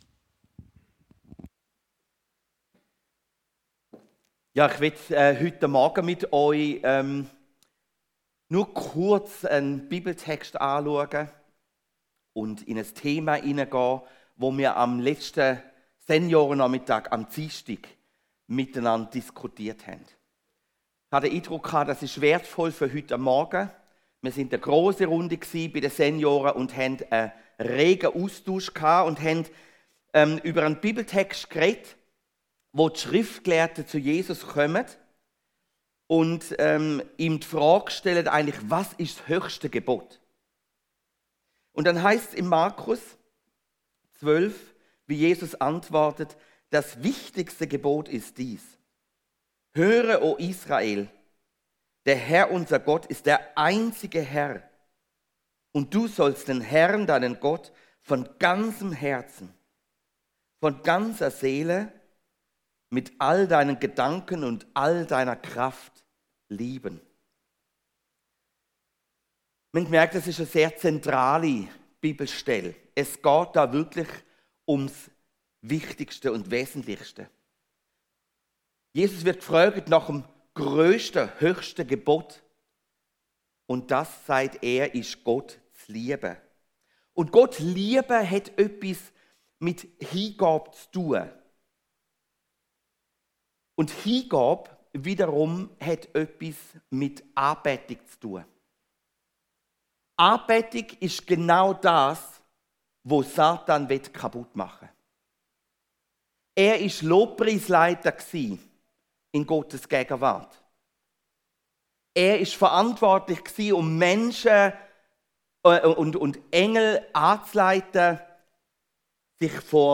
Hier hörst du die Predigten aus unserer Gemeinde.